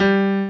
De modo similar ao de cima, agora outra nota (Sol) foi analisada pelo mesmo método:
Estes sons fazem parte de um banco de notas sintetizadas artificialmente com o timbre de um piano e foram disponibilizadas na internet.